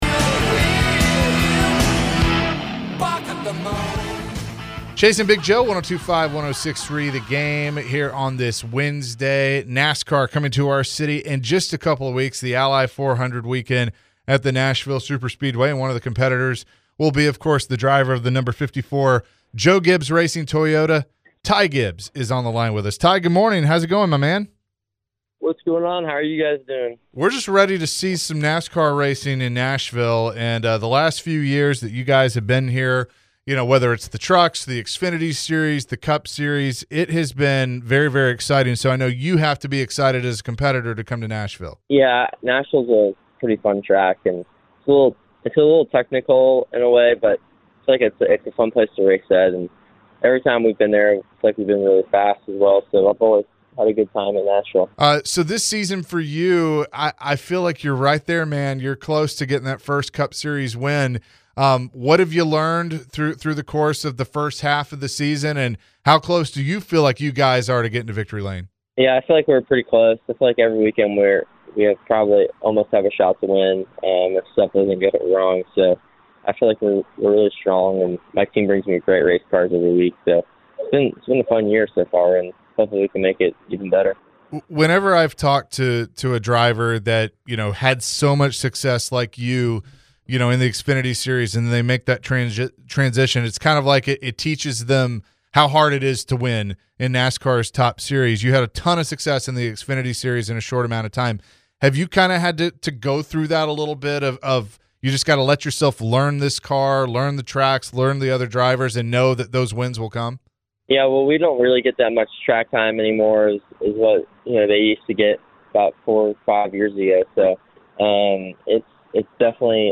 NASCAR Driver Ty Gibbs joined the Chase & Big Joe Show after the Iowa Corn 350. Ty talked about his journey racing in NASCAR. He also mentions his strong family support group around him. Ty was asked about his grandfather and racing team.